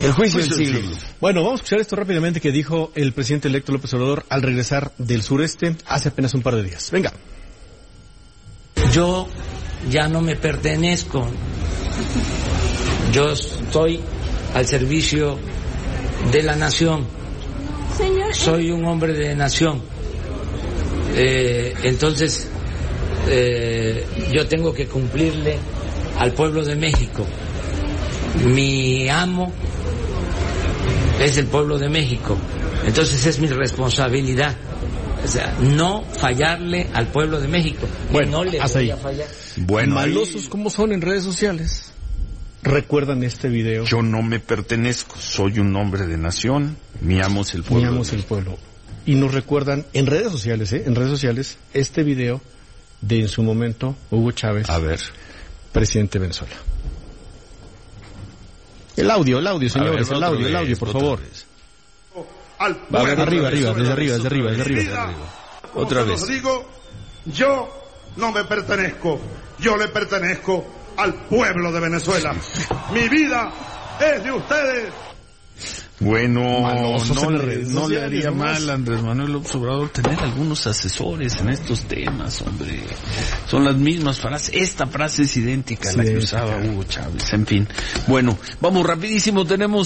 Difunden Audio De AMLO Dando Discurso “Chavista”